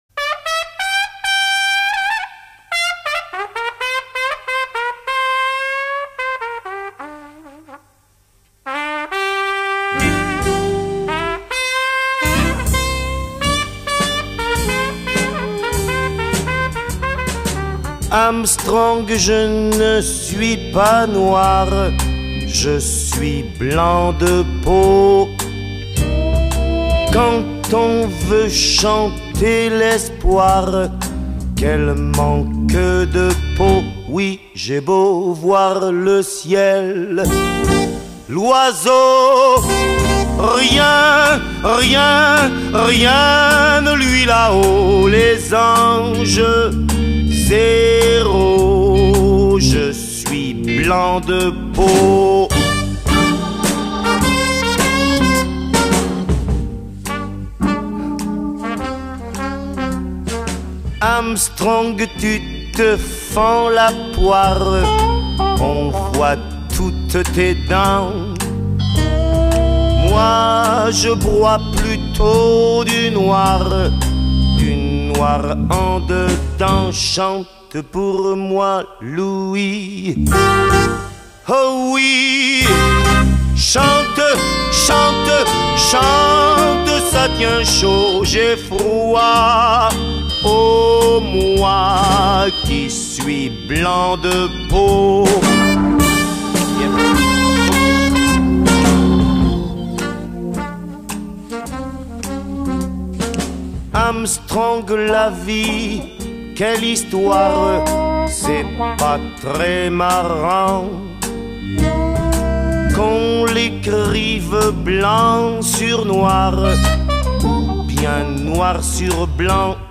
Negro spiritual traditionnel
Version originale